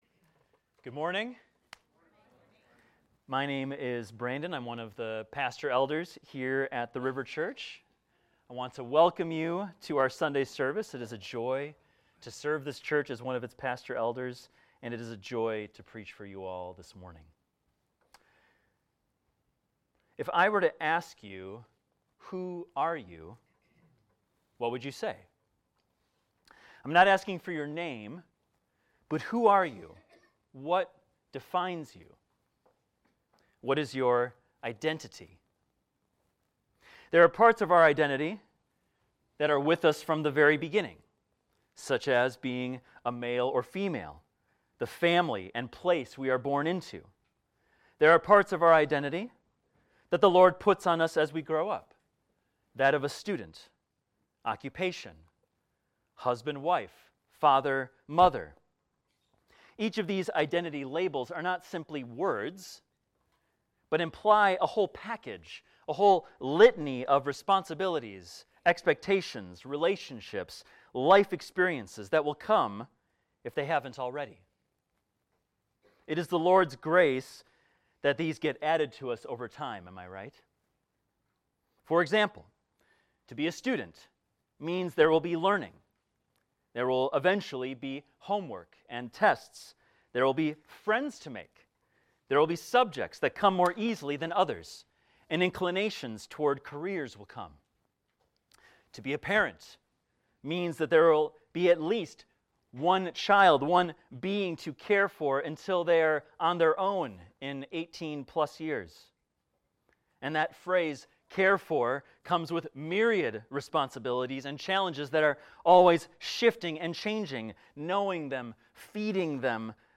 This is a recording of a sermon titled, "We Are Witnesses."